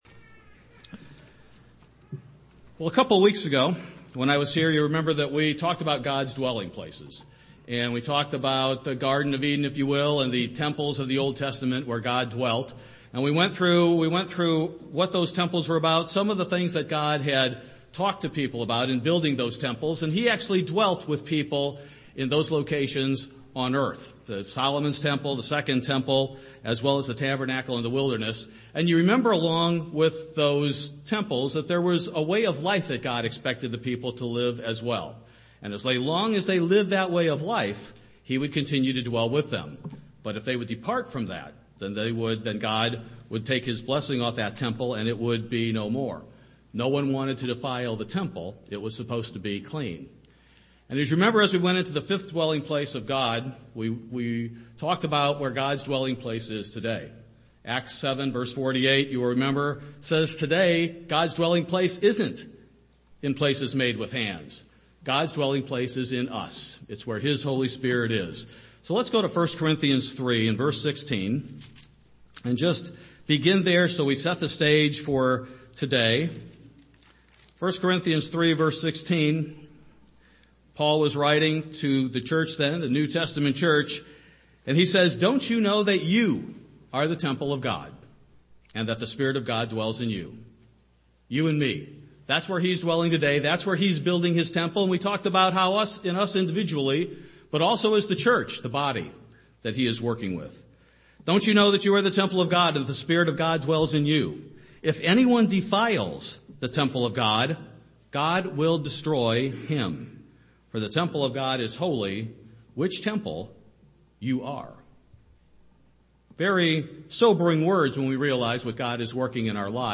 Part 2 of a three-part sermon on where God dwells. Will there be a third temple before Christ returns?